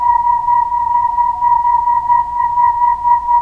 healing-soundscapes/Sound Banks/HSS_OP_Pack/Percussion/alien3_f.wav at a9e67f78423e021ad120367b292ef116f2e4de49
alien3_f.wav